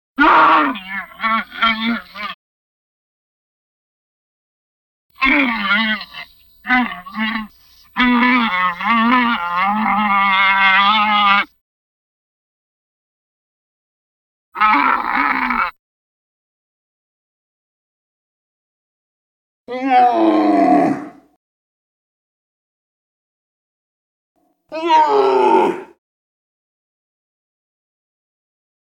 دانلود آهنگ شتر از افکت صوتی انسان و موجودات زنده
دانلود صدای شتر از ساعد نیوز با لینک مستقیم و کیفیت بالا
جلوه های صوتی